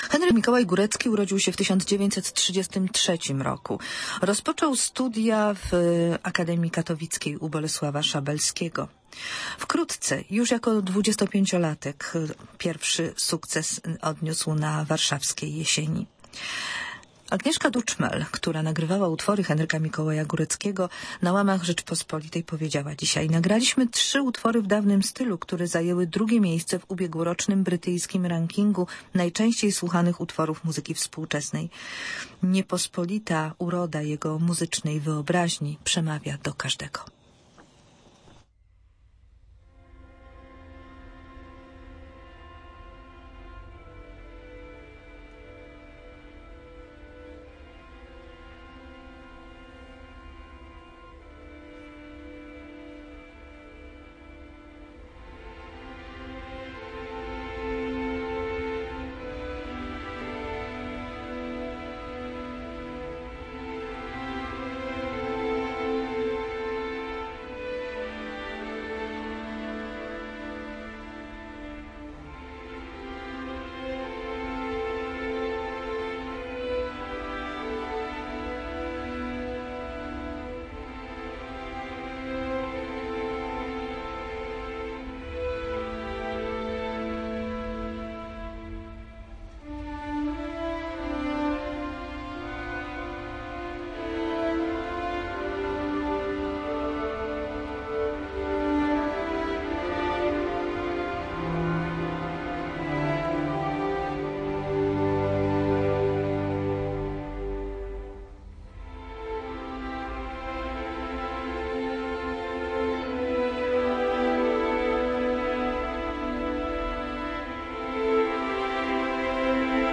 W związku ze śmiercią wybitnego polskiego kompozytora Henryka Mikołaja Góreckiego sięgnęliśmy do nagrań archiwalnych, między innymi kiedy twórca w 2007 roku odbierał Nagrodę im. Jerzego Kurczewskiego.